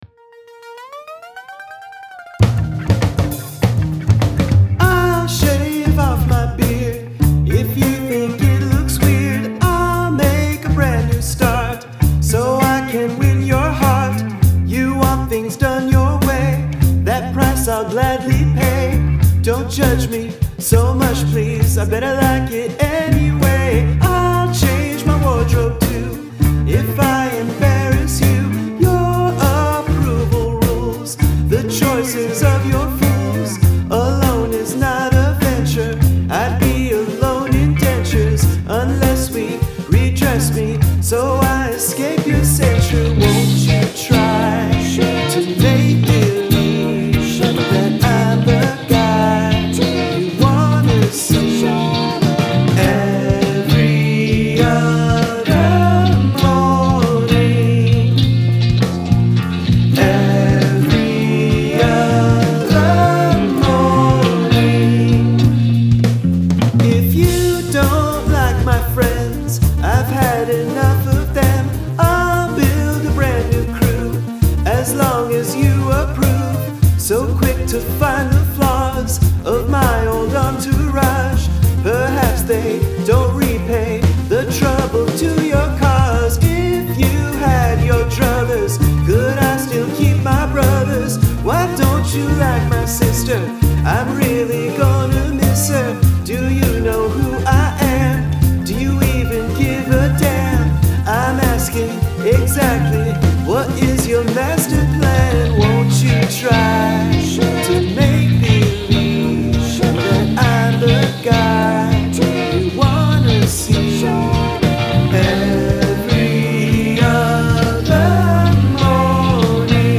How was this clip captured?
Lush ear-pleasing production.